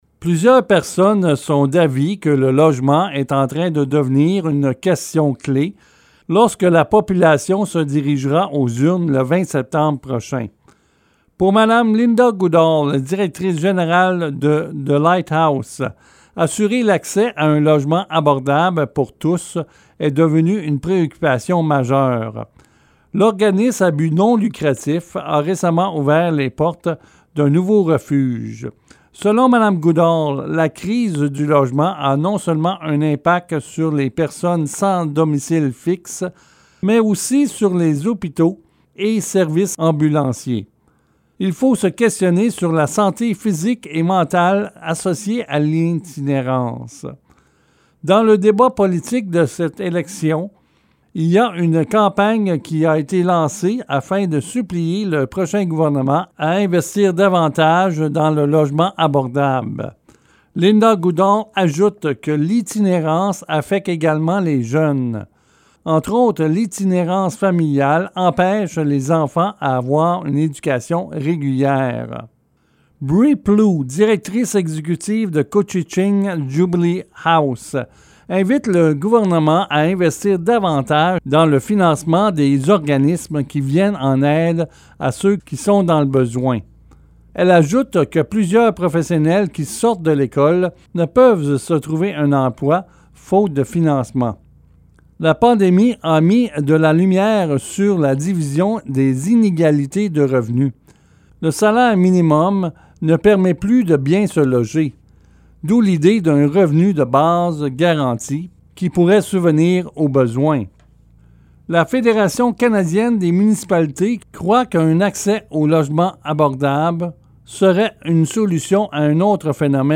Je vous dresse un petit résumé des questionnements et demandes de groupe local dans mon reportage :